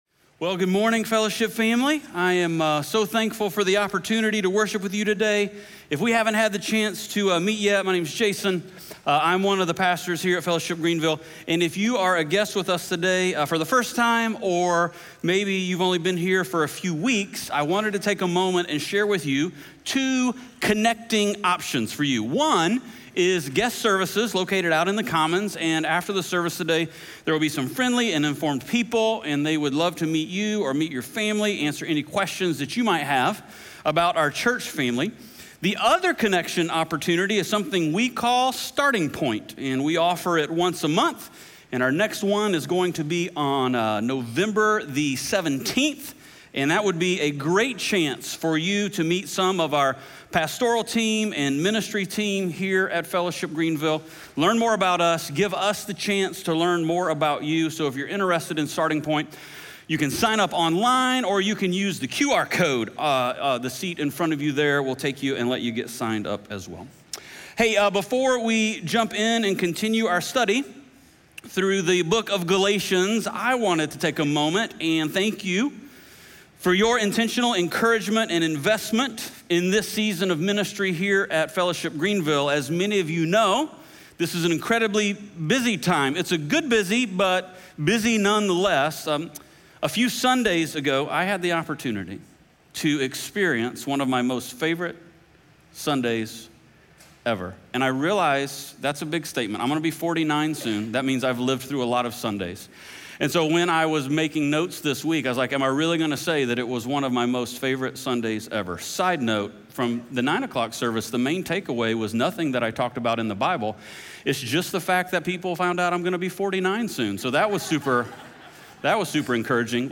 Galatians 3:10-22 Audio Sermon